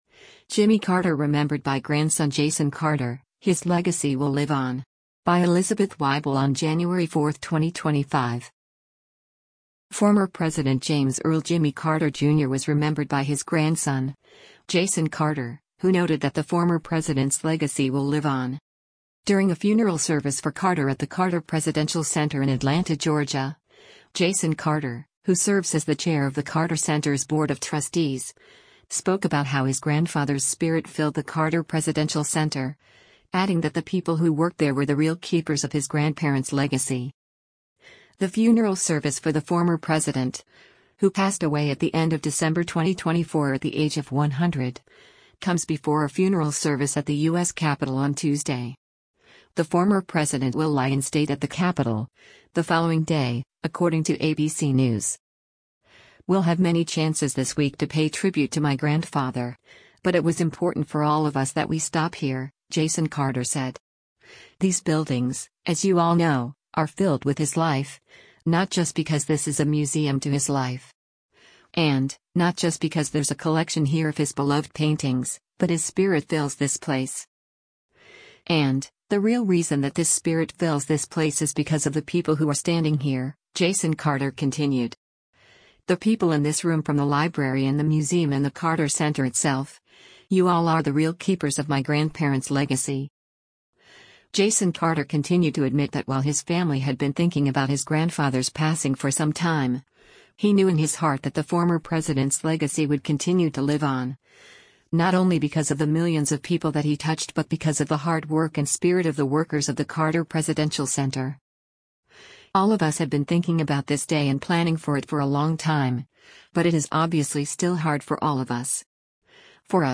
During a funeral service for Carter at the Carter Presidential Center in Atlanta, Georgia, Jason Carter, who serves as the Chair of the Carter Center’s Board of Trustees, spoke about how his grandfather’s spirit filled the Carter Presidential Center, adding that the people who worked there were “the real keepers” of his grandparent’s legacy.